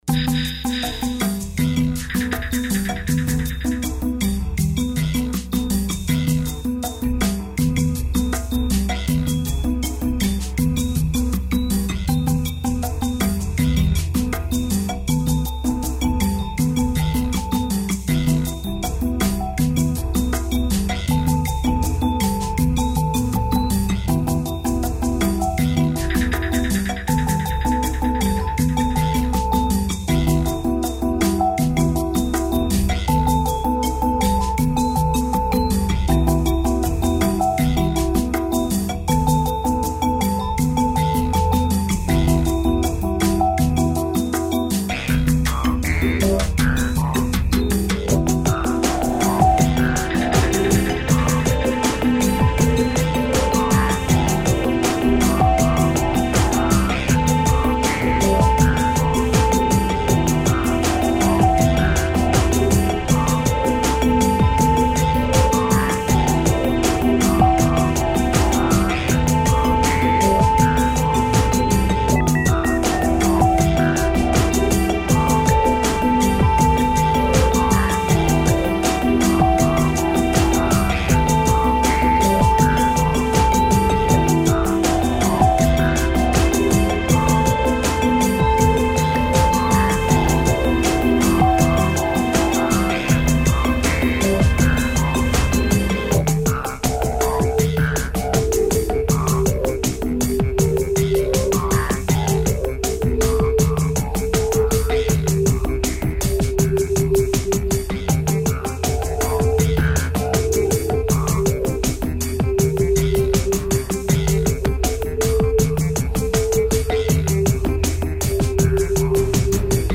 dance/electronic
Ambient
World beats